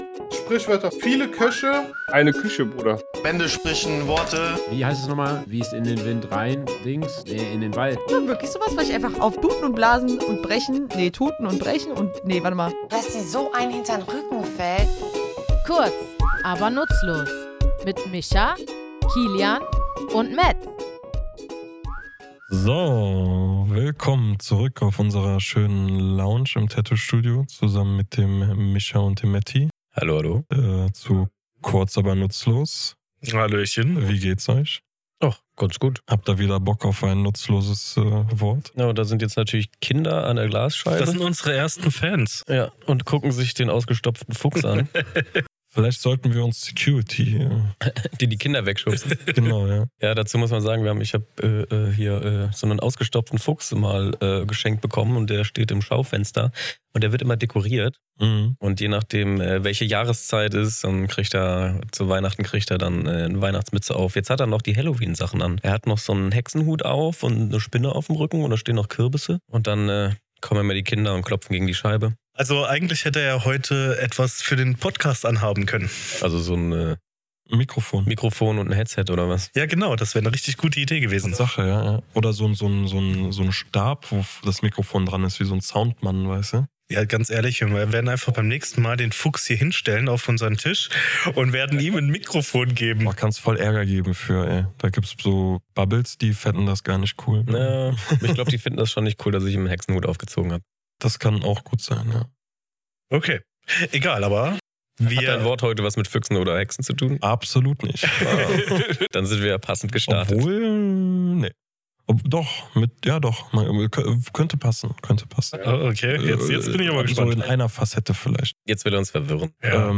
Was bedeutet es, wenn jemand keinen Erfolg hat, und warum spielt ein grüner Zweig dabei eine Rolle? Wir, drei tätowierende Sprachenthusiasten, klären in unserem Tattoostudio die Herkunft und Geschichte dieser Redewendung.